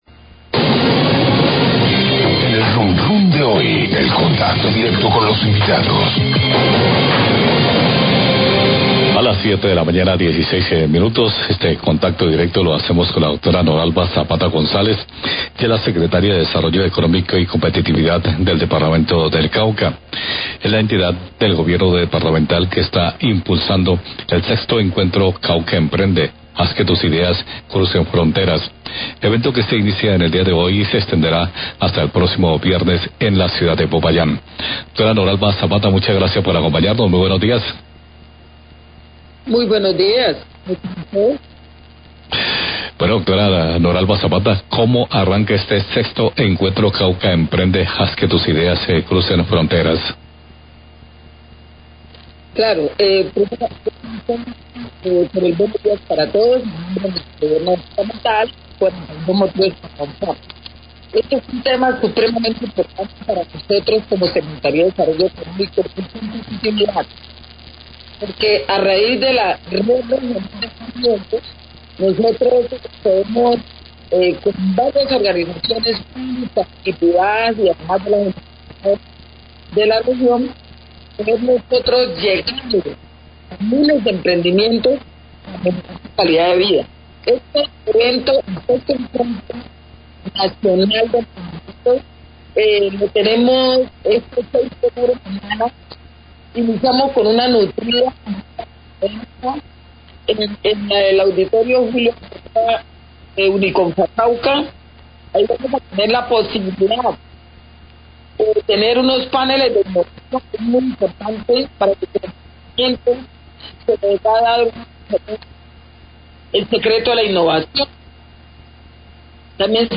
En entrevista Noralba Zapata, Secretaria de Desarrollo Económico y Competitividad del Cauca, entidad del Gobierno Departamental que impulsa el evento 'VI Encuentro Cauca Emprende: Haz que tus ideas crucen fronteras', evento que inicia hoy hasta el 7 de octubre en Unicomfacauca y una muestra comercial en el parque Caldas.